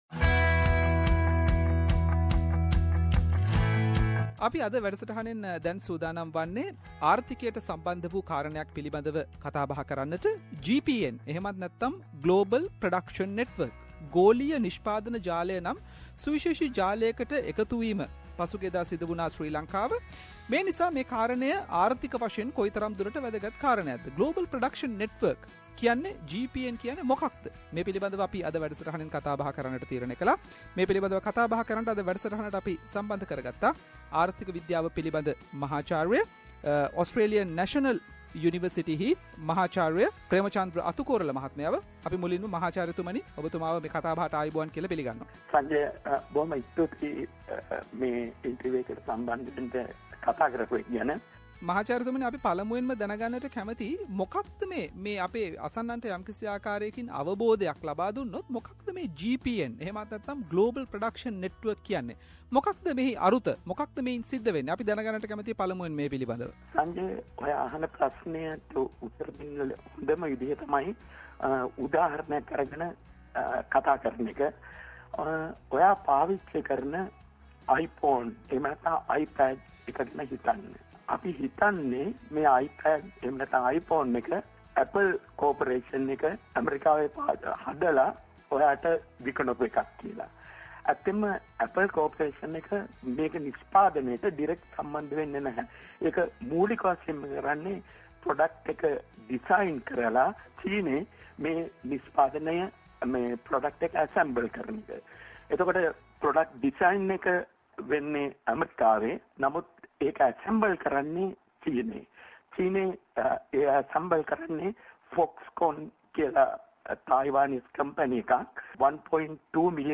Special interview with economist